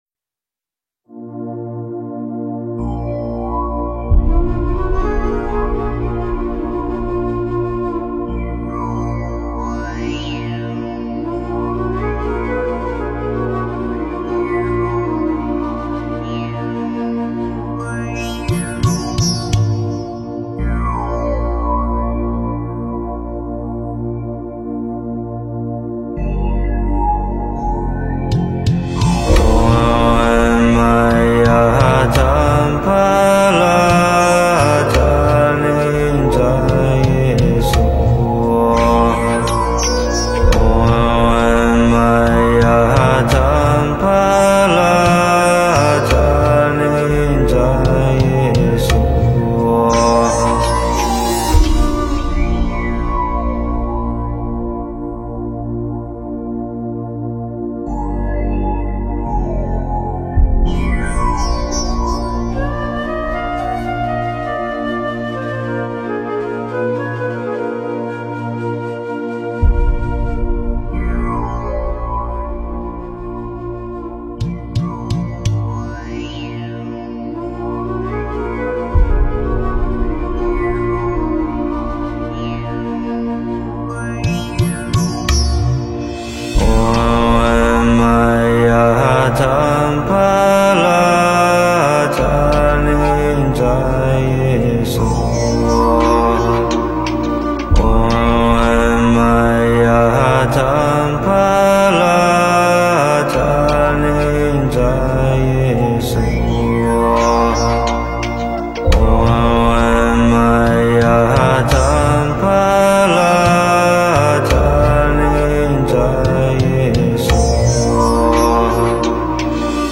诵经
佛音 诵经 佛教音乐 返回列表 上一篇： 大悲咒(梵音